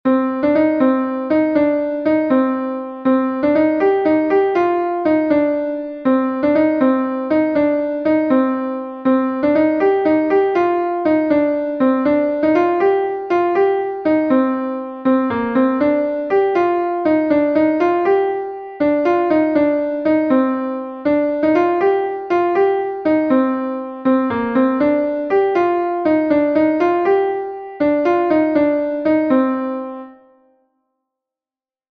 Ton Bale Pondivi est un Bale de Bretagne